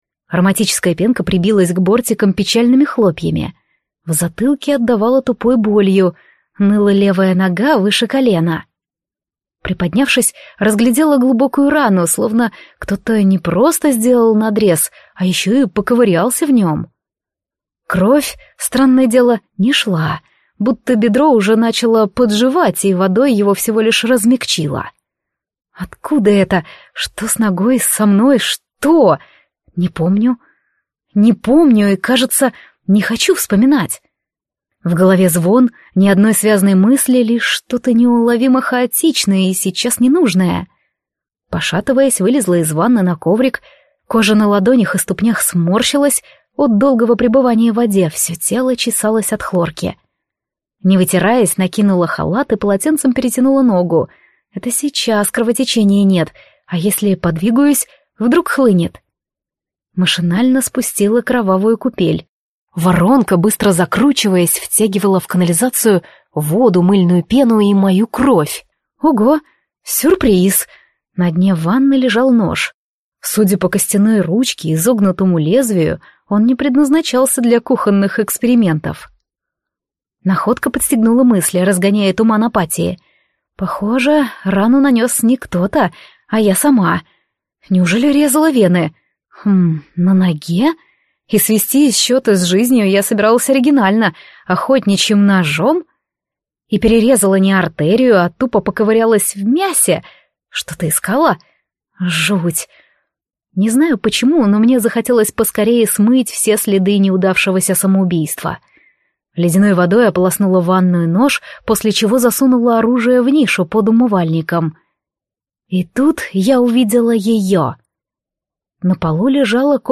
Аудиокнига Ее темные рыцари | Библиотека аудиокниг
Прослушать и бесплатно скачать фрагмент аудиокниги